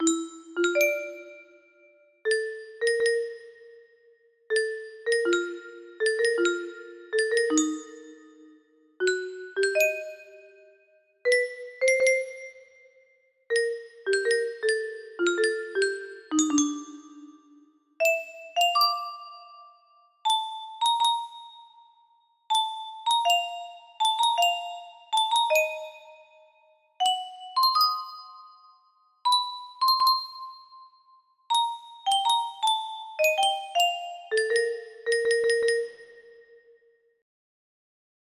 Sangre vienesa tema principal music box melody